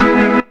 B3 EMAJ 2.wav